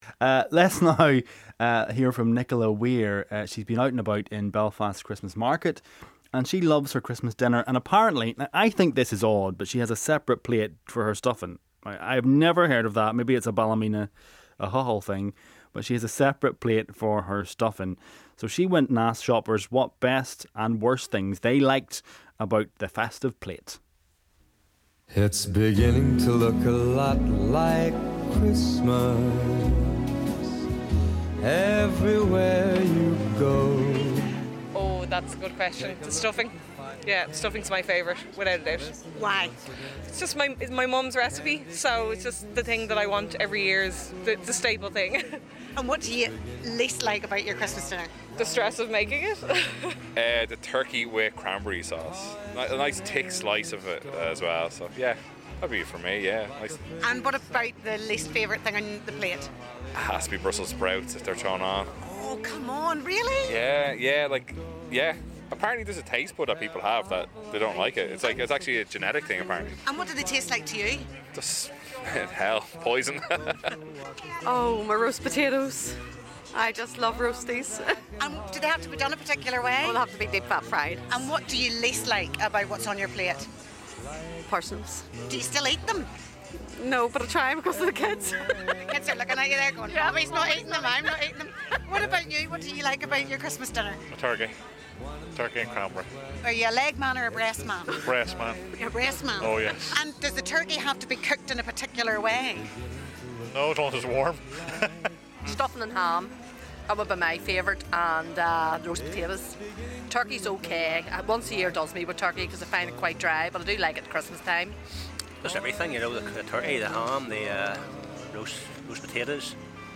was talking turkey at the Belfast Christmas Market